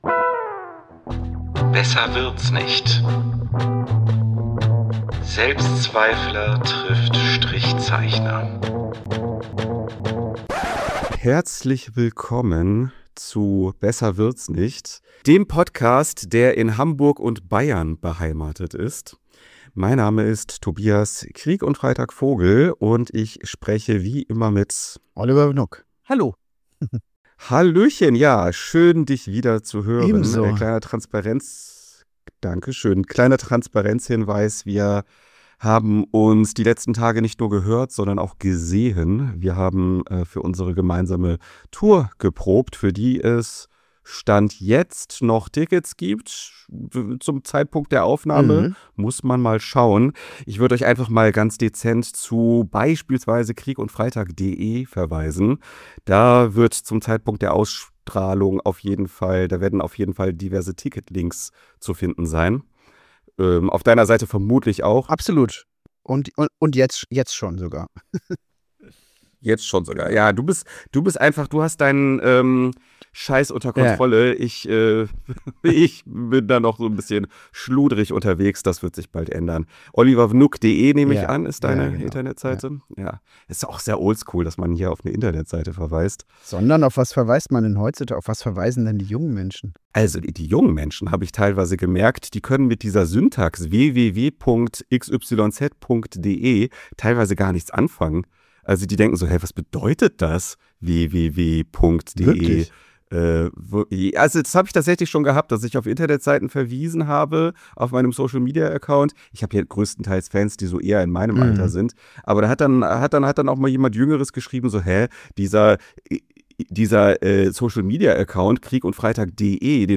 Wir singen zum Glück nicht, sondern sprechen über Heimat, was sie für uns bedeutet und warum es so verflucht schwierig ist, bei diesem Thema nicht politisch zu werden.
Trotzdem ist die Folge unverhofft harmonisch und hinterlässt bei den Hörenden hoffentlich ein ebenso warmes Gefühl wie bei den beiden Hosts.